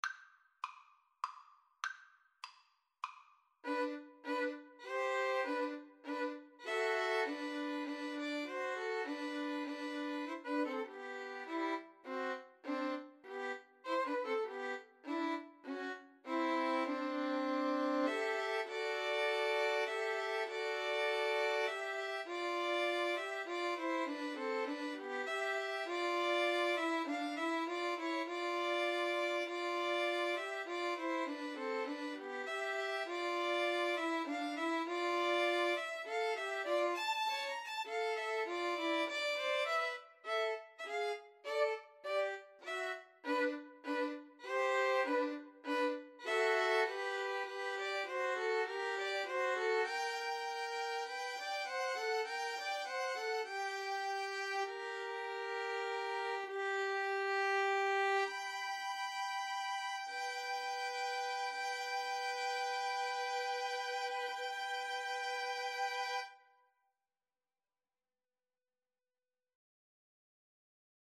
G major (Sounding Pitch) (View more G major Music for Violin Trio )
3/4 (View more 3/4 Music)
~ = 100 Allegretto grazioso (quasi Andantino) (View more music marked Andantino)
Violin Trio  (View more Intermediate Violin Trio Music)
Classical (View more Classical Violin Trio Music)